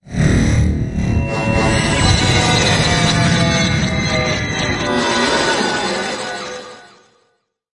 科幻的声音效果 (52)
从ZOOM H6录音机和麦克风Oktava MK01201领域录制的效果，然后处理。
Tag: 未来 托管架 无人驾驶飞机 金属制品 金属 过渡 变形 可怕 破坏 背景 游戏 黑暗 电影 上升 恐怖 开口 命中 噪声 转化 科幻 变压器 冲击 移动时 毛刺 woosh 抽象的 气氛